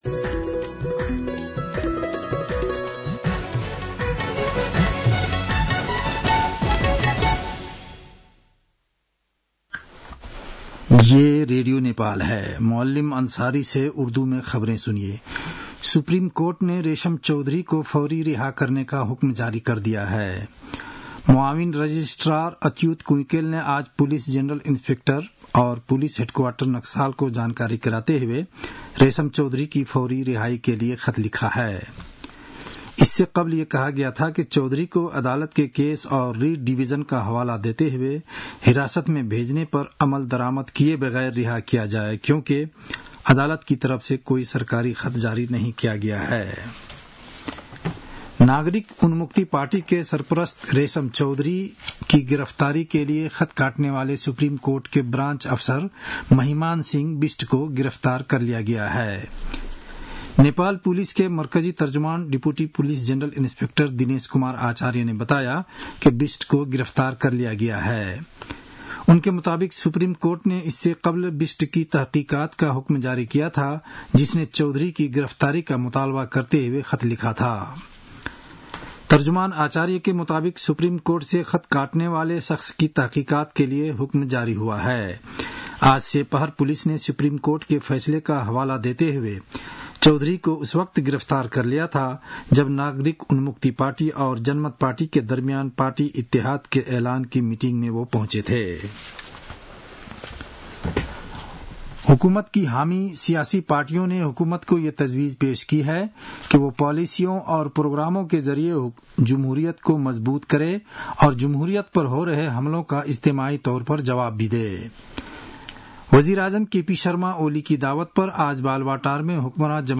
उर्दु भाषामा समाचार : १७ वैशाख , २०८२